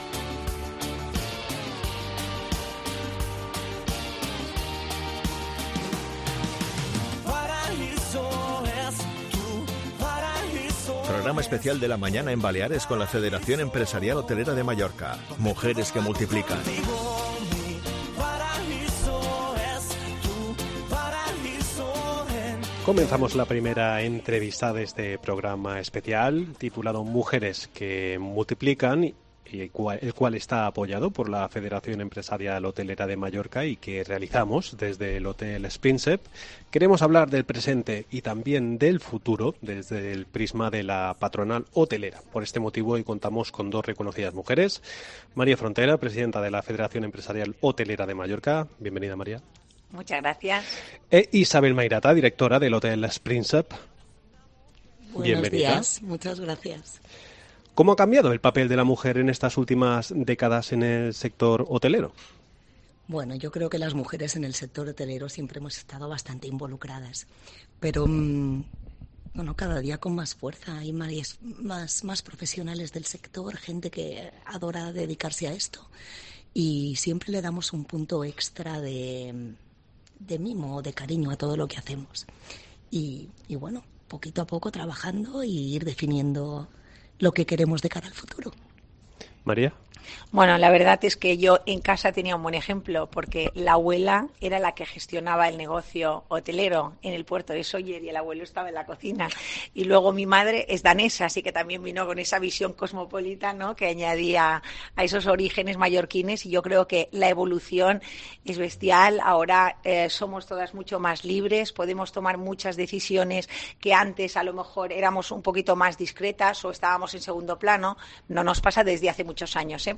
La Mañana COPE Mallorca Programa especial Mujeres que multiplican, apoyado por FEHM. Entrevista
En este programa especial dedicado a la Federación Empresarial Hotelera de Mallorca y que realizamos desde el Hotel Es Princep, queremos hablar del presente y también del futuro desde el primas de la patronal hotelera.